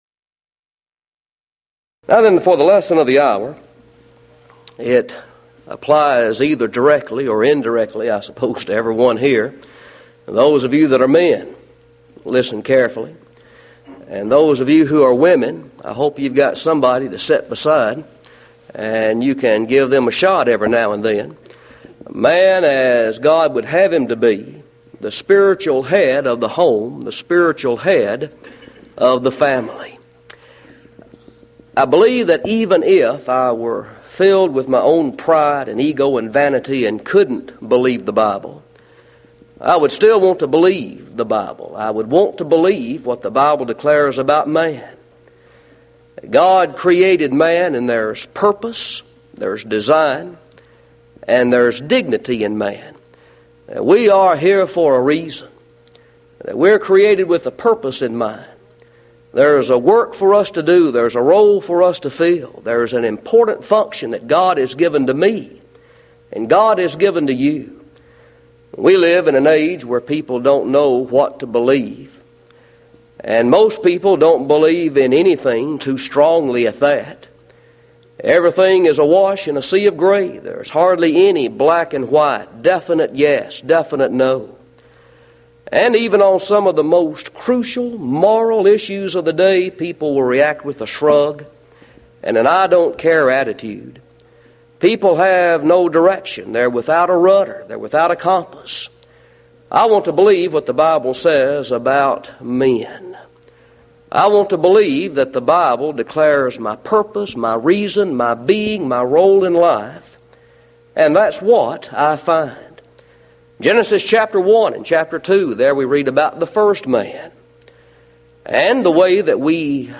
Event: 1993 Mid-West Lectures
lecture